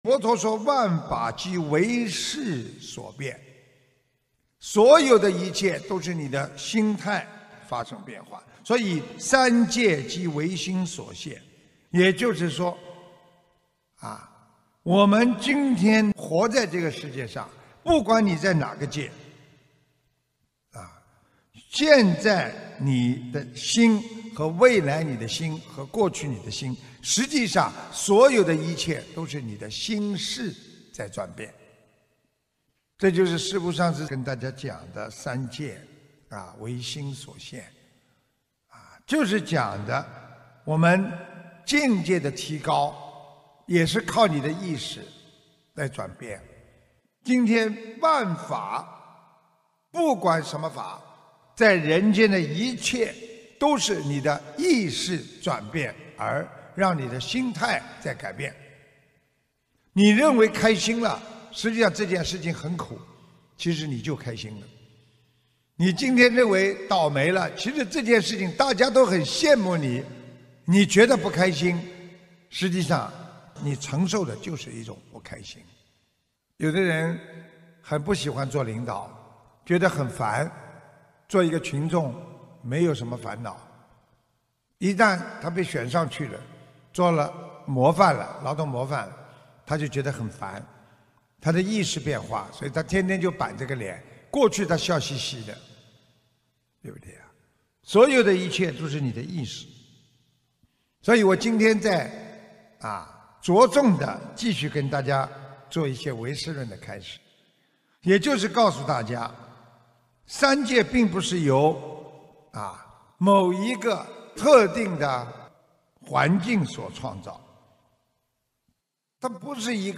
音频：学佛人要懂得是和非都是错！香港玄艺综述现场解答会开示摘要！